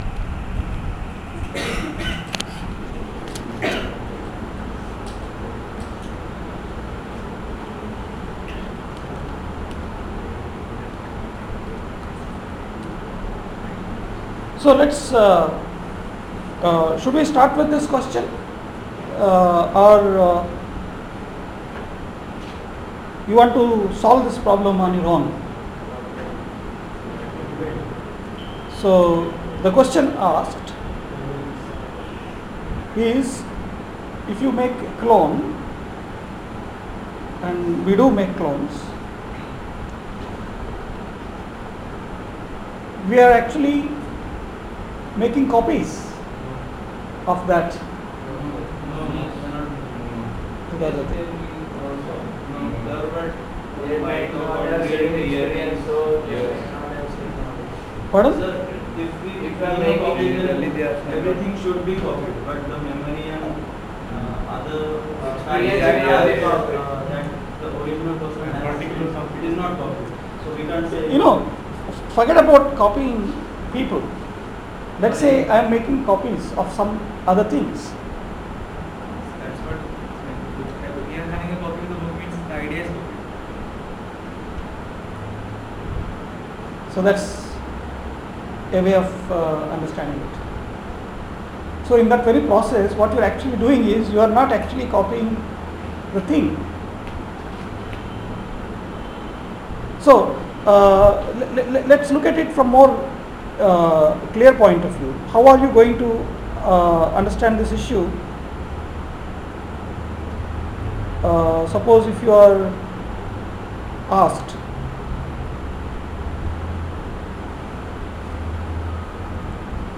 lecture 5